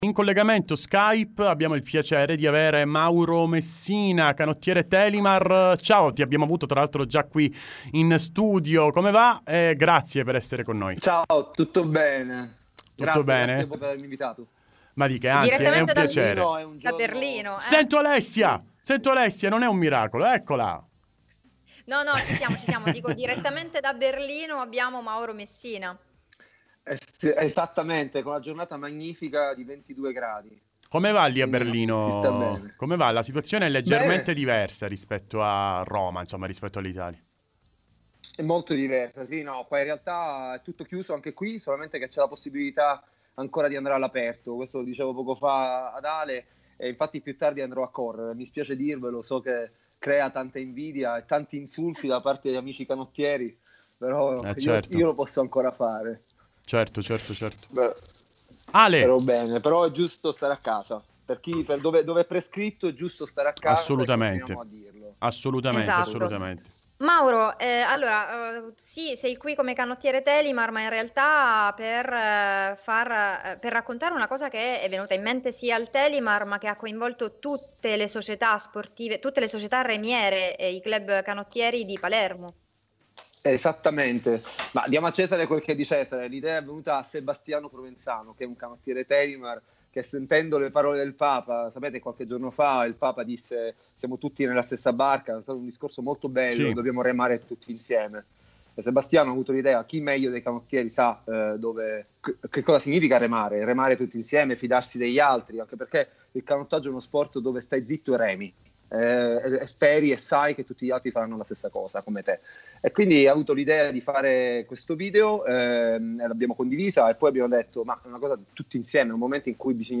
Time Sport Intervista